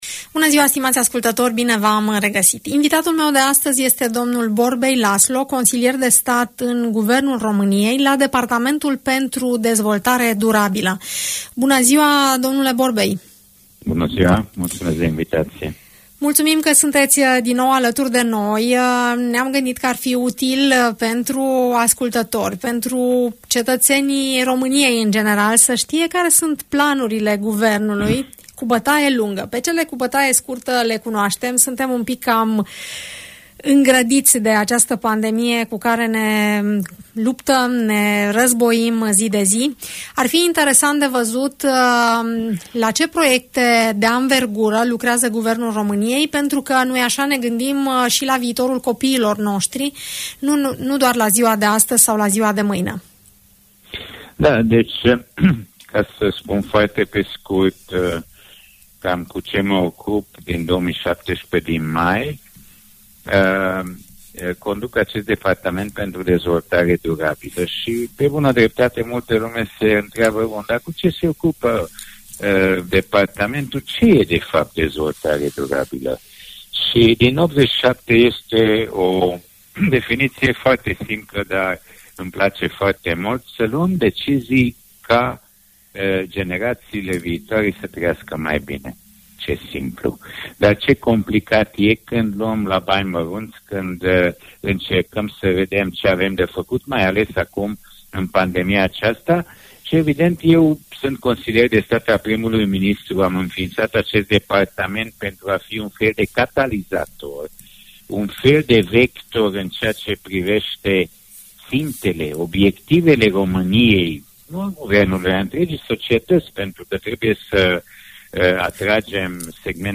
Care sunt proiectele de viitor ale României, am încercat să aflăm de la Borbely Laszlo, secretar de stat în Guvernul României, în cadrul Departamentul pentru Dezvoltare Durabilă.